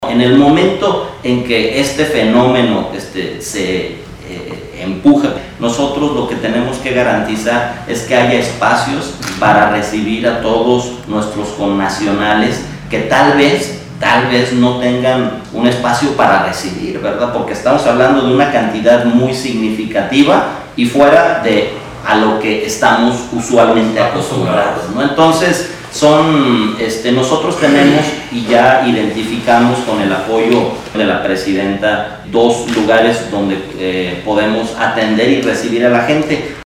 AudioBoletines
Rodolfo Gómez Cervantes, secretario del ayuntamiento